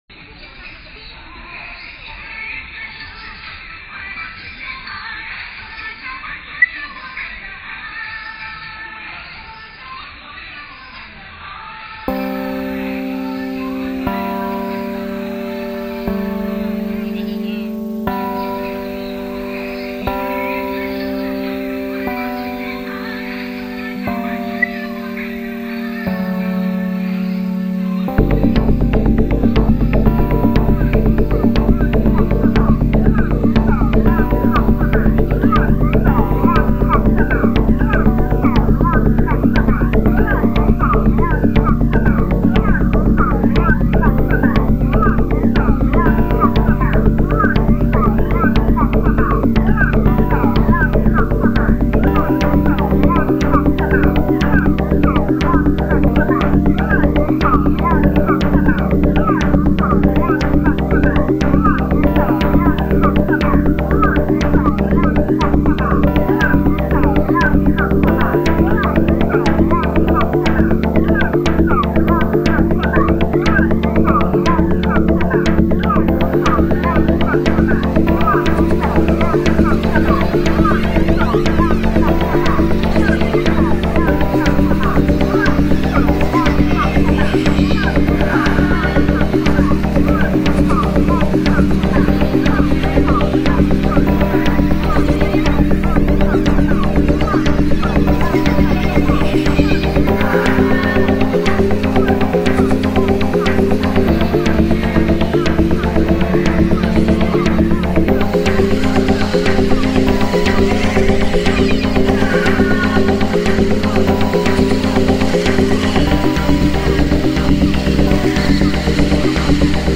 Shanghai street festival reimagined